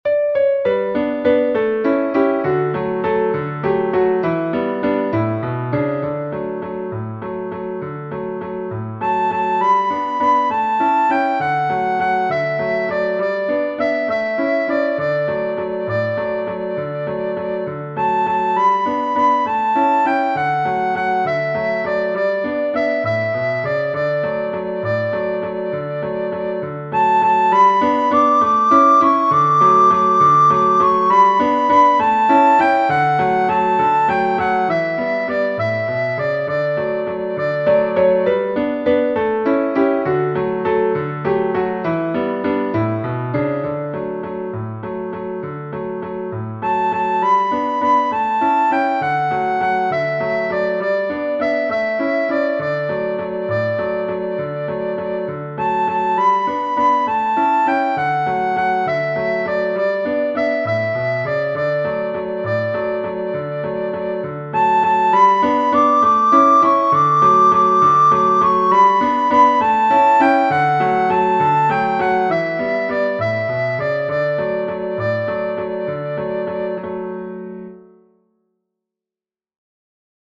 Genere: Religiose Canto natalizio veneziano